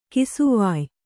♪ kisuvāy